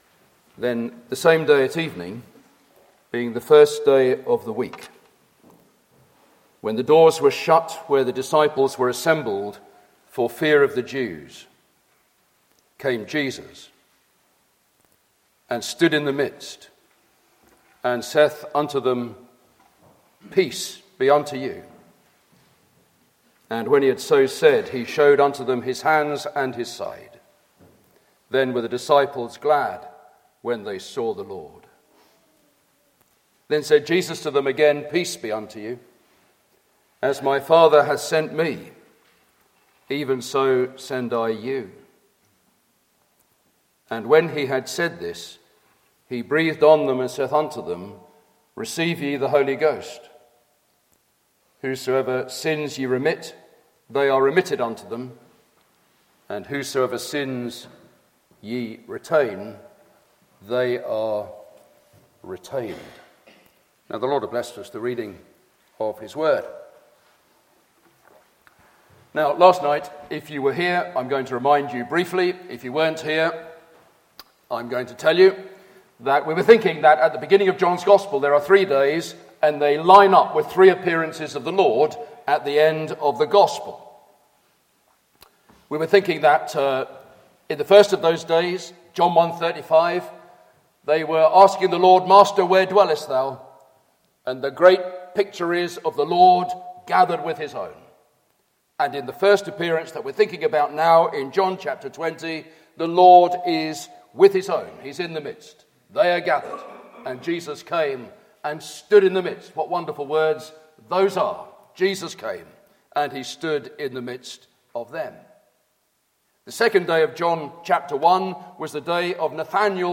Meeting Type: Ministry